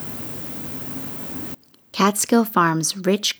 The volume is super quiet.
It’s noisy. I made the first second louder on purpose so you can hear the noises. Is that your computer cooling or air conditioning back there?